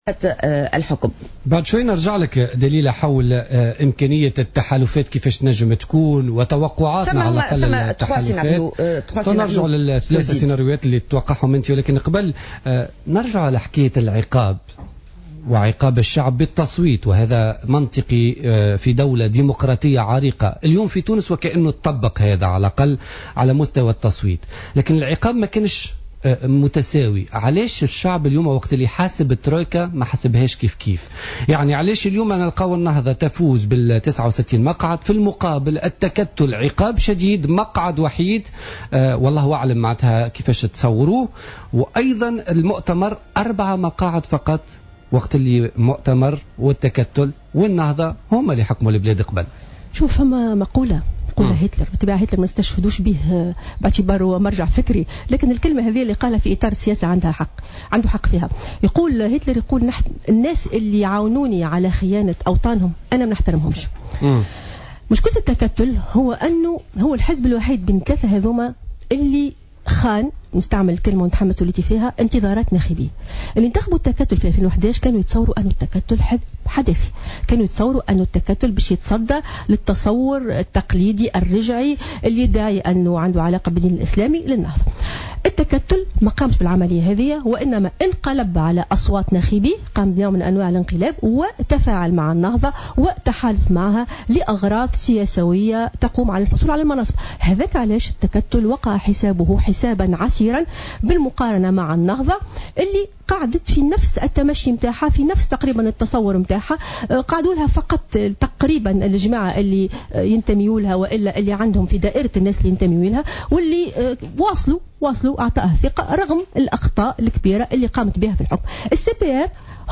قال الدكتورة والباحثة الفة يوسف ضيفة برنامج بوليتيكا اليوم الجمعة 31 أكتوبر 2014 أن التكتل هو من بين الثلاث احزاب الذي خان انتظار ناخبيه سنة 2011 ولذلك كان تم اقصاءه في انتخابات 2014 وكان أشد احزاب الترويكا عقابا على حد قوله.